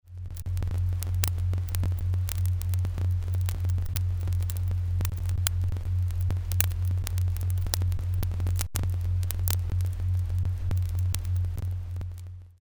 Note: If you’re using small speakers or ear buds you may not be able to hear the low-pitched (<100Hz) tones in these sound clips.
This short sound clip is a continuous recording of the motion of the ground at a seismic observatory in Australia over a span of 18 months.[1] I’ve sped it up a whopping 4,000,000 times to make the sound of this “solid Earth tide” audible.
At first hearing, it may sound like little more than a scratchy old vinyl LP recording. But don’t be deceived: that scratchiness is actually the sound of thousands of earthquakes, large and small, around the world. If you listen carefully, you’ll hear a faint low-pitched hum hiding beneath that “noise”.
Recorded December 2002-June 2003 at IDA station WRAB (Tennant Creek, Australia). Broadband seismometer, vertical component, sampled at 20Hz.